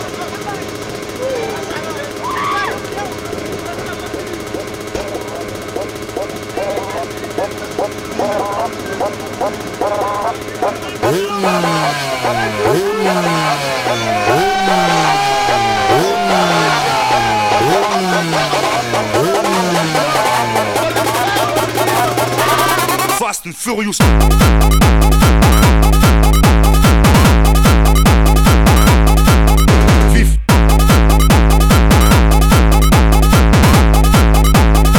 Жанр: Пост-хардкор / Хард-рок